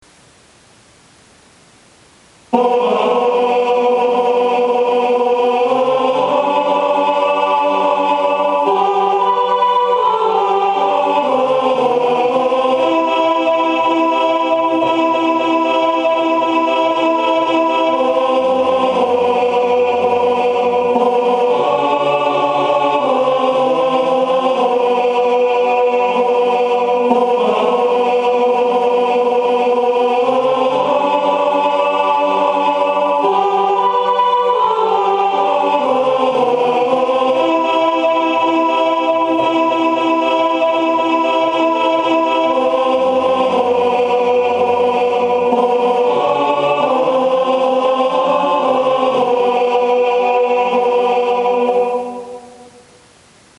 Hierna  de klankband van de alt- en basstem van het lied
160916 alt- Een stad boven wolken.MP3 (419.8 KB)